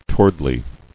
[to·ward·ly]